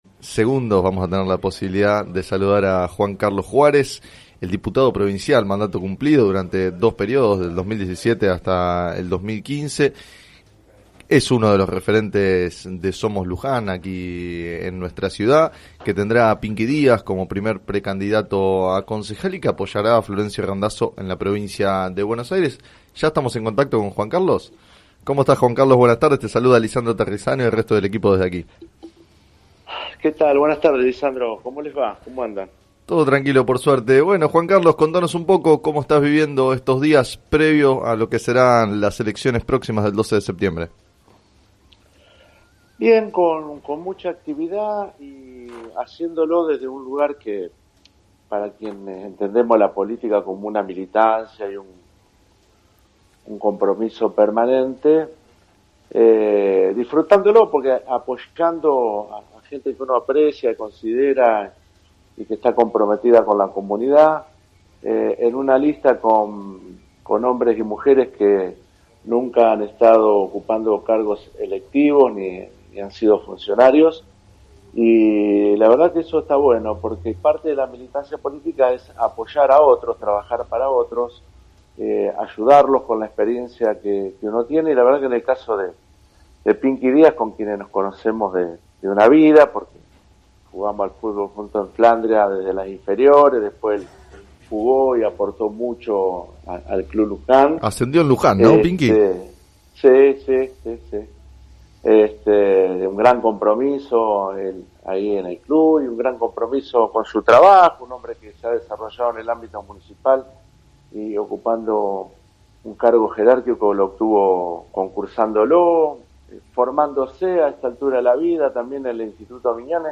En declaraciones al programa “Sobre las cartas la mesa” de FM Líder 97.7